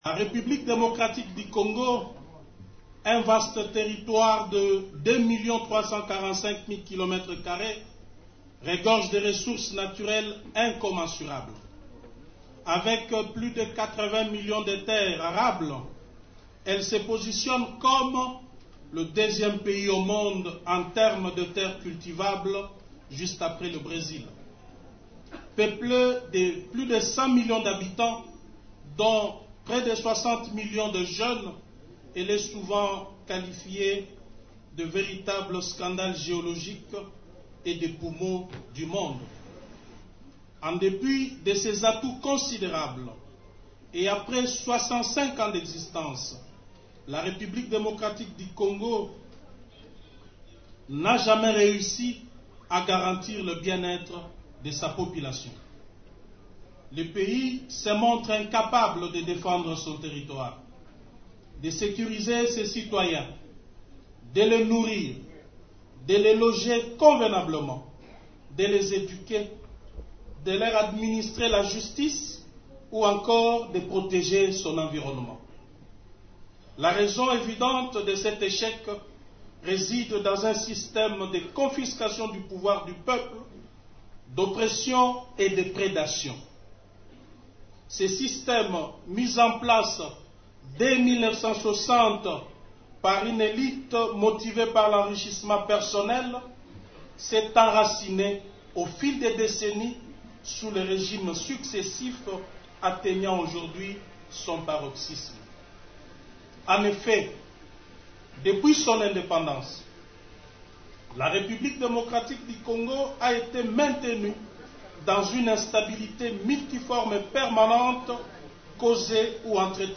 Des milliers de militants et sympathisants des partis membres de la Coalition ont pris d’assaut, ce mercredi, la salle des conférences de Show-Buzz à Kinshasa, pour assister à cette sortie politique officielle.
Suivez un extrait du discours de Jean-Marc Kabund: